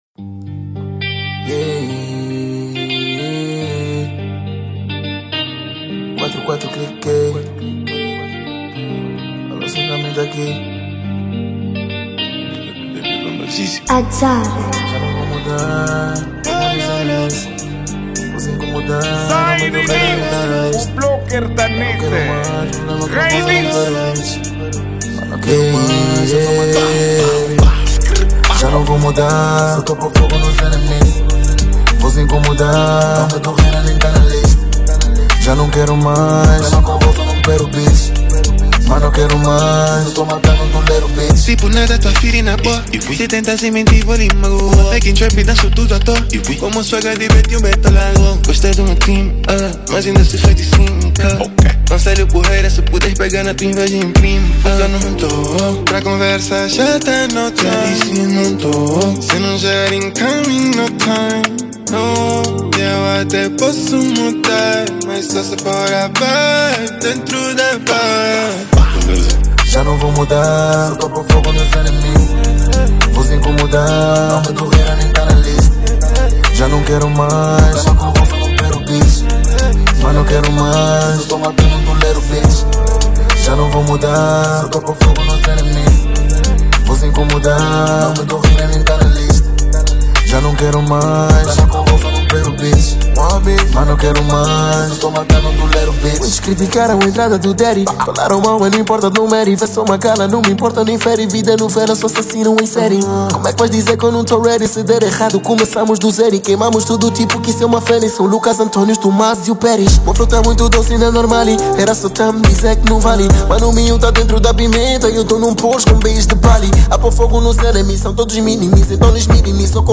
Genero:Rap